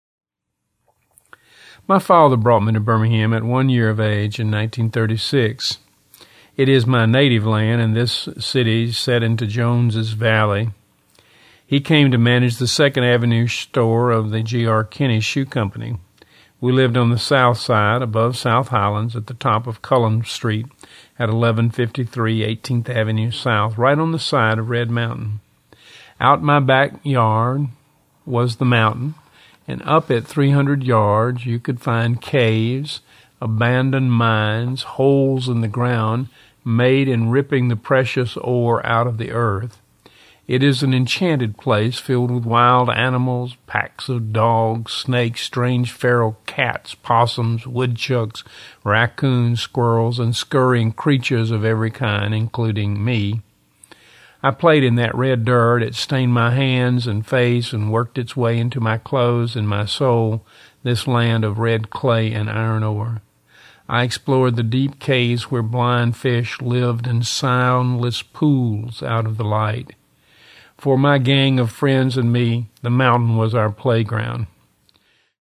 This was recorded in a room in my house with modest acoustic treatments and the reader backed up against a moving pad.
Well read too, by the way.